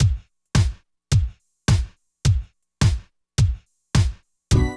47 DRUM LP-R.wav